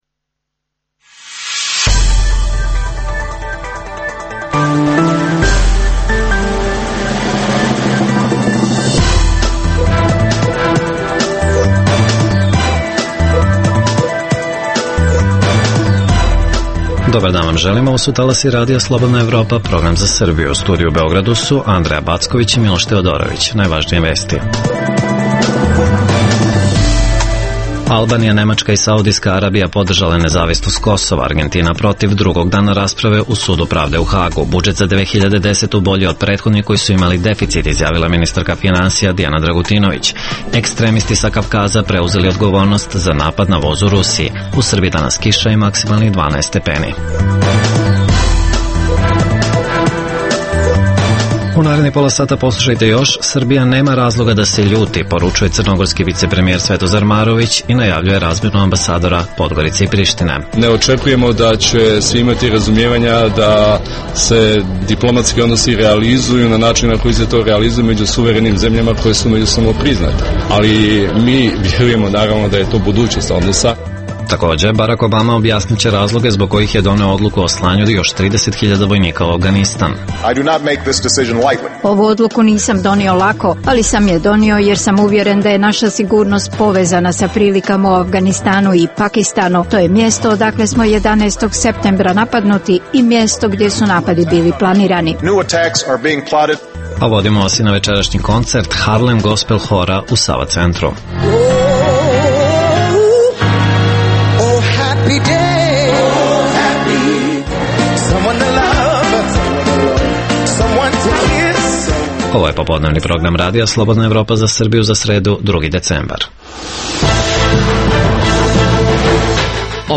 O stavu koji su drugog dana rasprave pred Međunarodnim sudom pravde u Hagu zauzele Albanija, Nemačka, Saudijska Arabija i Argentina izveštavamo sa lica mesta.